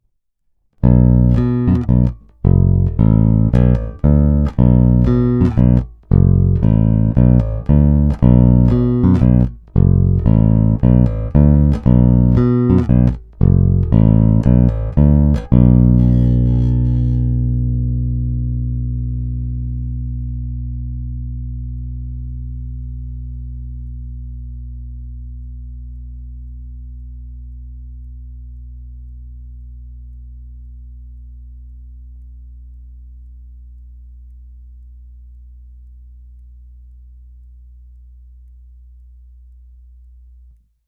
Pevný, kovově vrnící, poměrně agresívní, prosadí se.
Není-li uvedeno jinak, následující nahrávky jsou provedeny rovnou do zvukové karty, s plně otevřenou tónovou clonou a bez zařazení aktivní elektroniky.
Snímač u krku